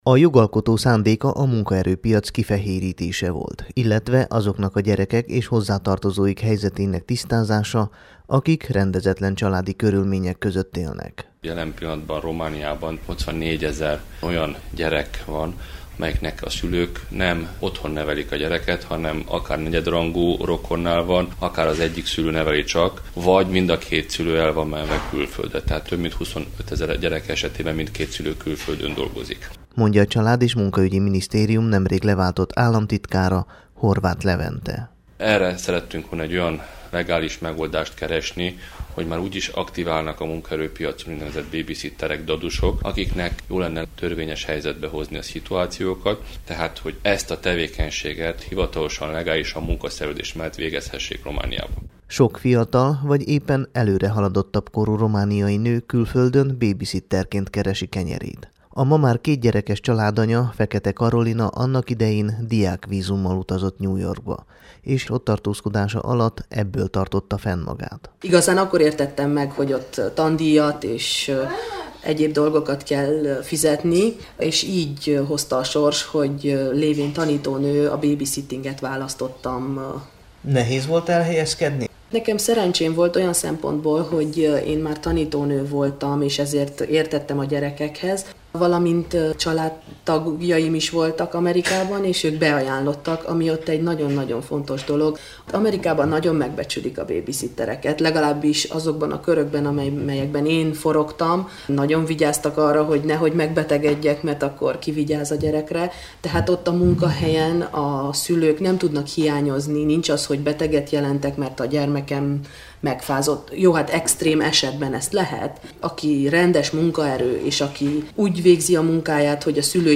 riportban